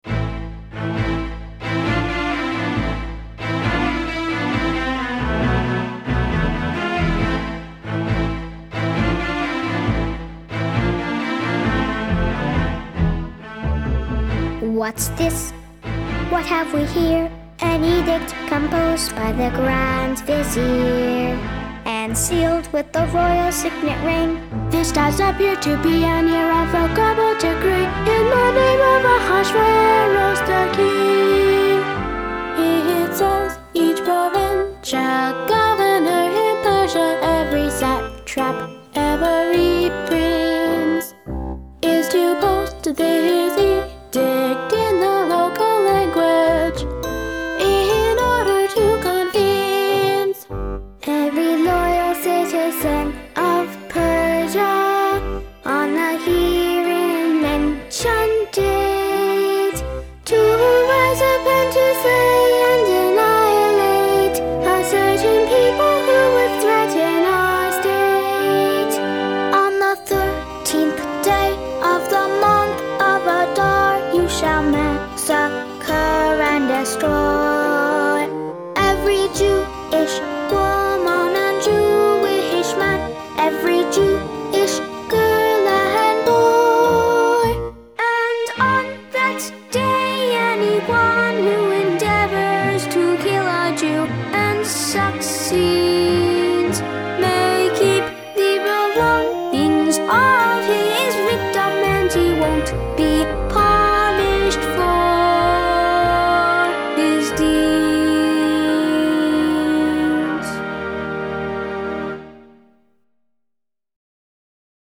The world premier production of this original opera by NCFO founder David Bass was performed at the King Open School, 850 Cambridge St, Cambridge, MA in late March, 2004.